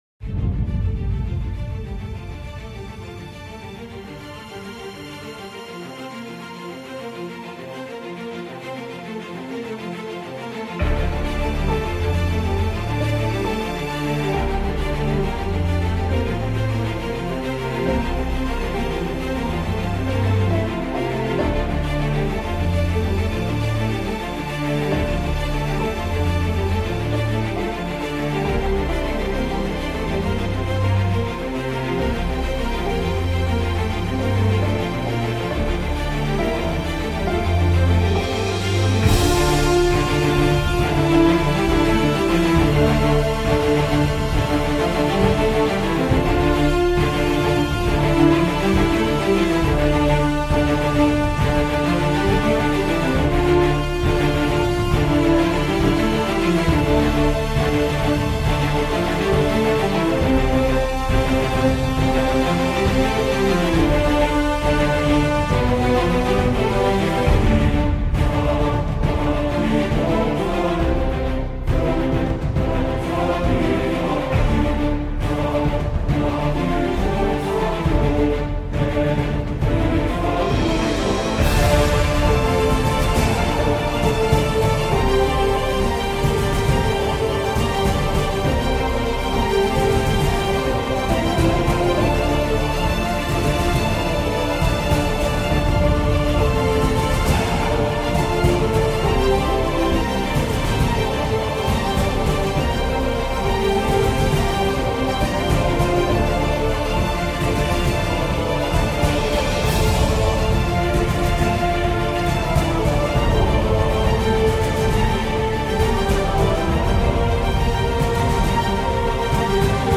Genre: Orchestral (Epic Drama)